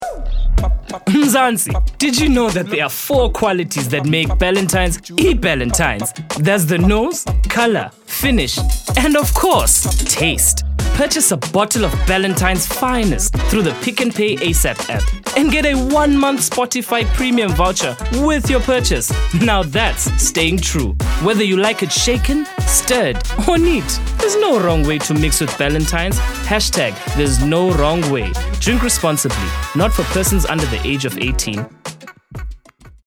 Vocal Styles:
energetic, playful, upbeat, youthful
Vocal Age:
My demo reels